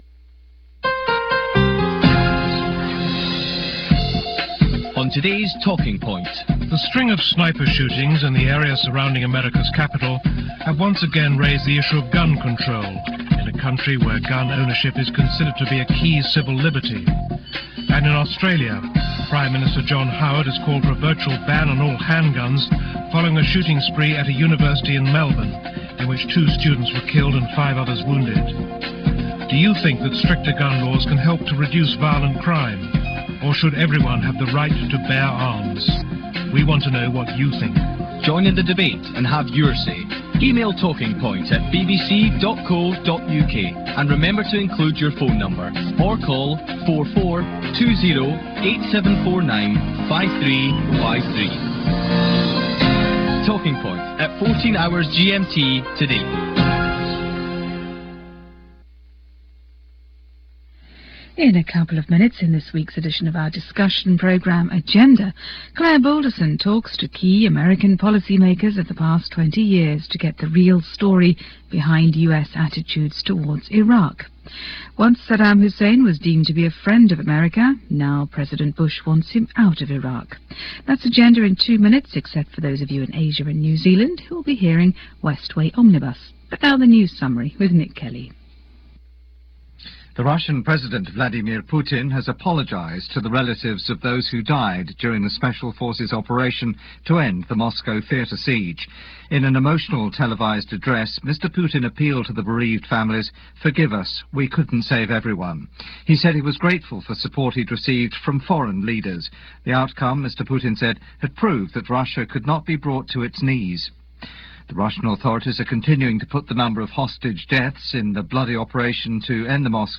And this is what it sounded like on October 27, 2002 as reported first, by BBC World Service and then by Radio Moscow English service news.